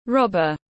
Kẻ cướp tiếng anh gọi là robber, phiên âm tiếng anh đọc là /ˈrɒb.ər/.
Robber /ˈrɒb.ər/